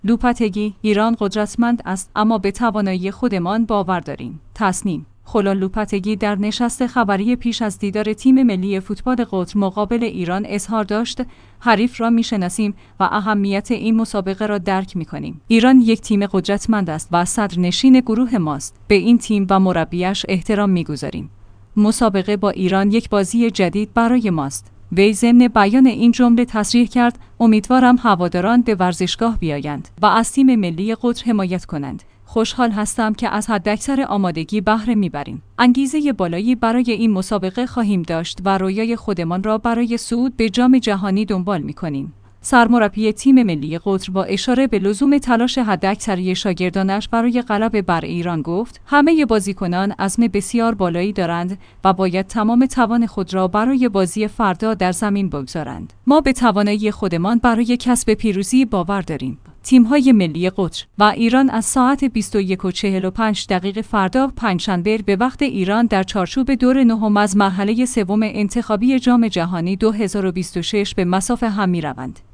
تسنیم/خولن لوپتگی در نشست خبری پیش از دیدار تیم ملی فوتبال قطر مقابل ایران اظهار داشت: حریف را می‌شناسیم و اهمیت این مسابقه را درک می‌کنیم.